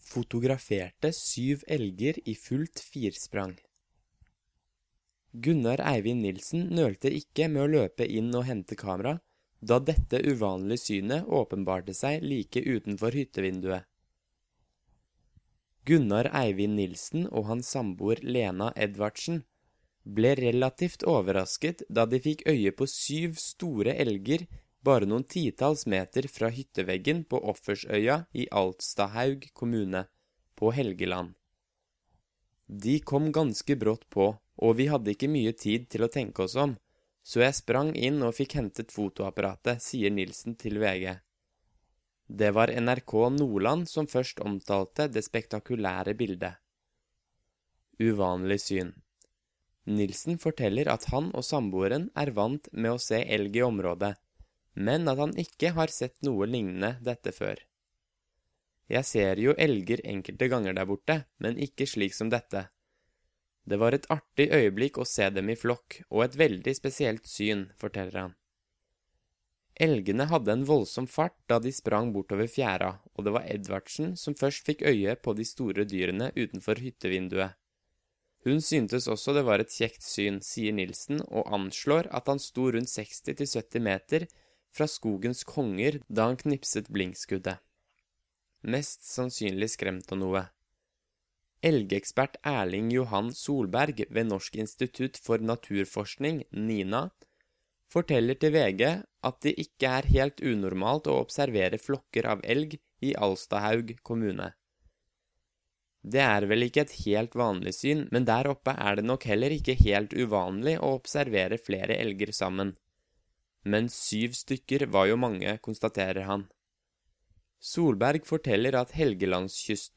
Learn Norwegian by listening to a native Norwegian while you’re reading along.